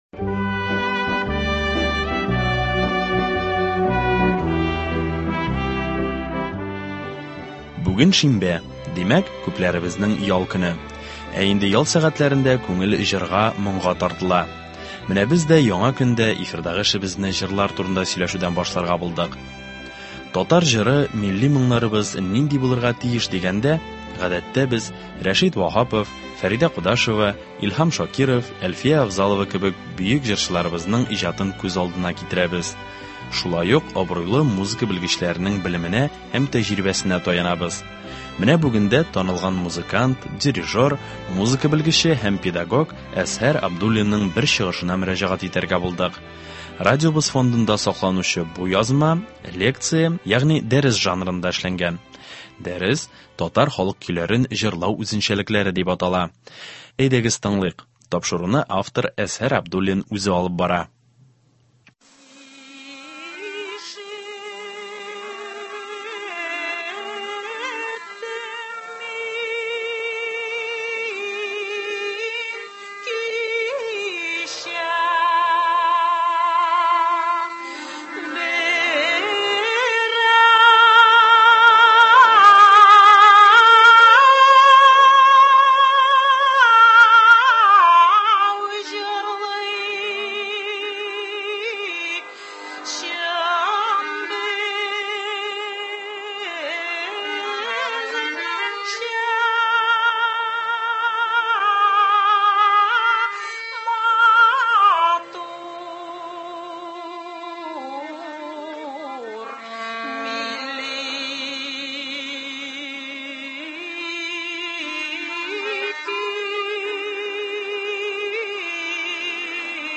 Әдәби-музыкаль композиция. 25 апрель.